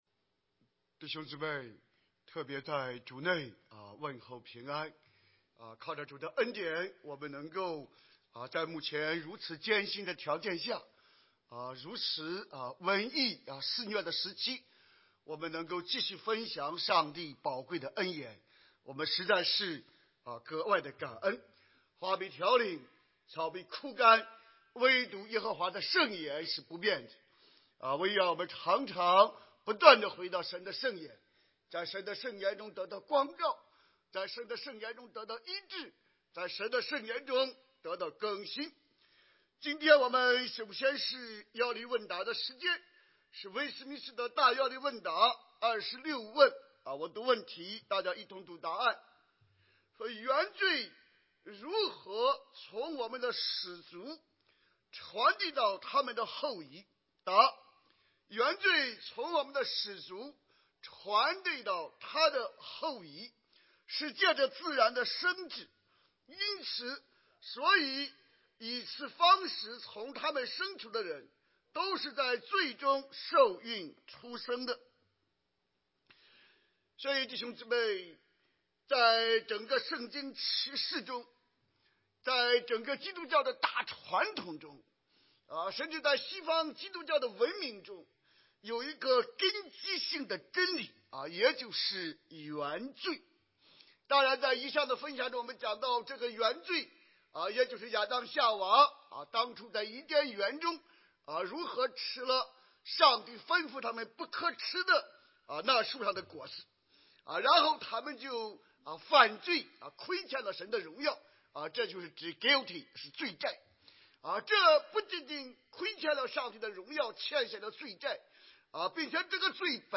主日證道